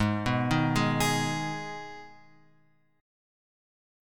G#m chord {4 2 1 1 x 4} chord